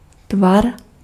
Ääntäminen
US : IPA : [ʃeɪp]